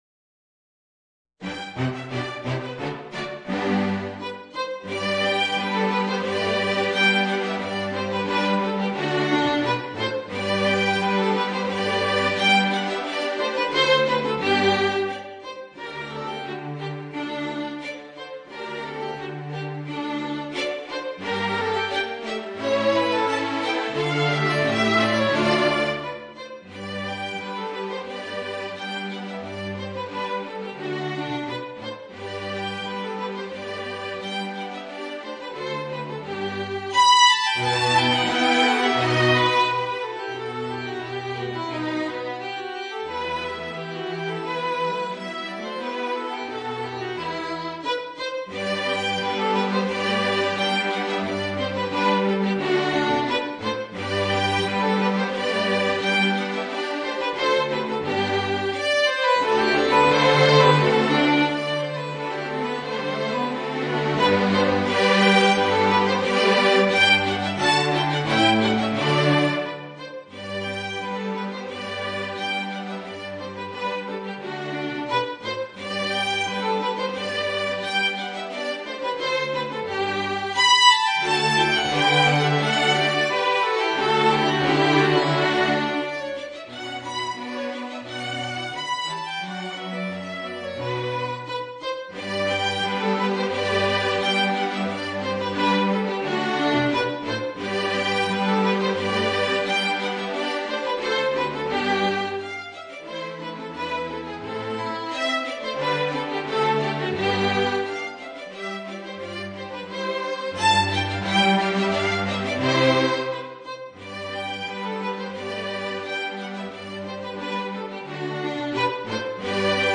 Voicing: Oboe and String Quartet